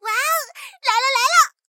SU-76补给语音.OGG